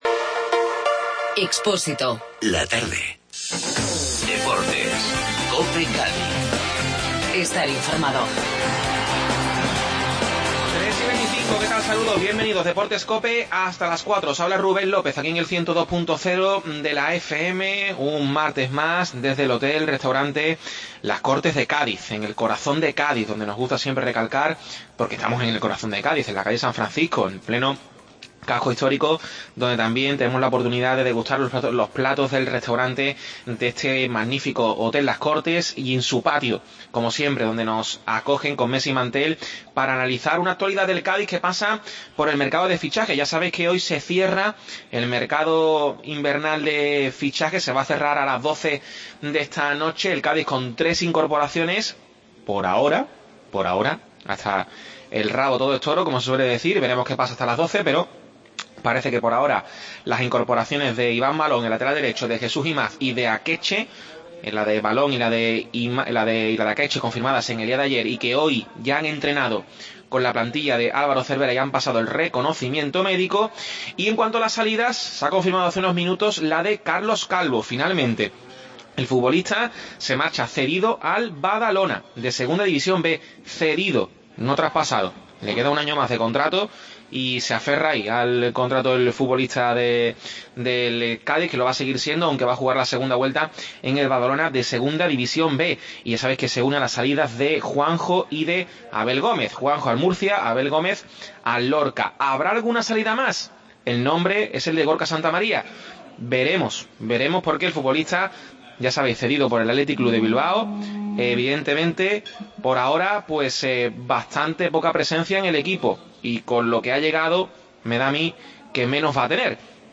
Desde el Hotel Las Cortes analizamos la actualidad del Cádiz